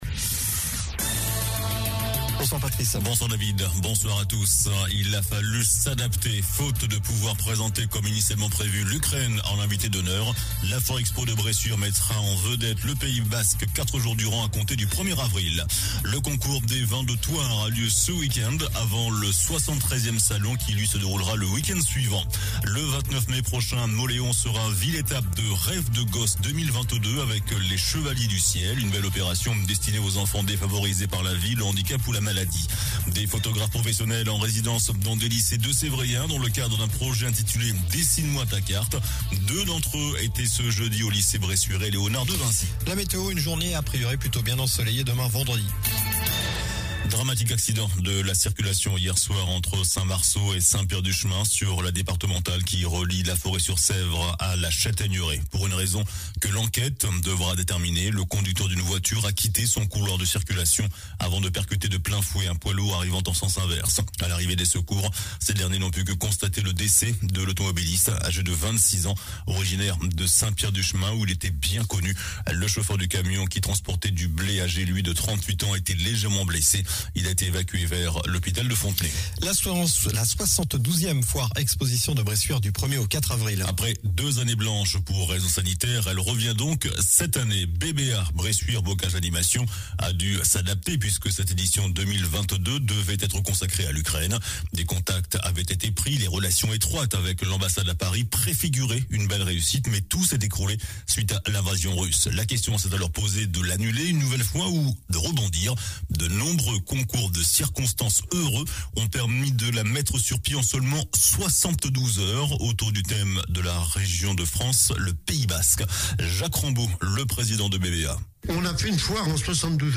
JOURNAL DU JEUDI 17 MARS ( SOIR )